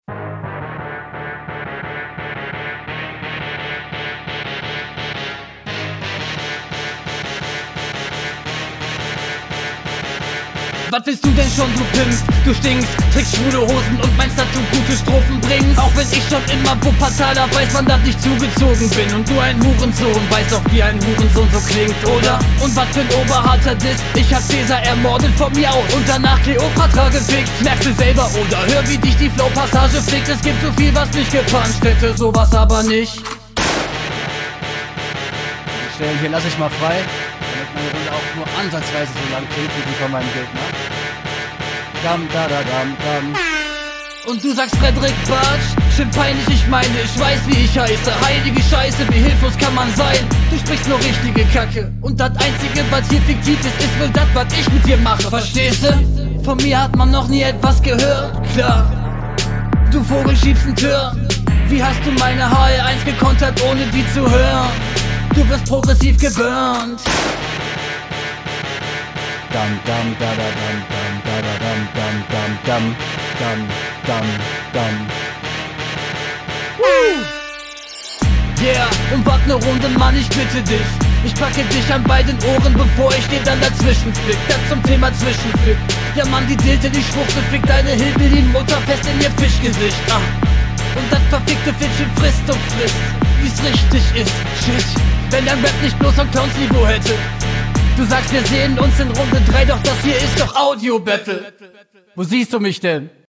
Der Flow und der Text sagt mir hier mehr zu.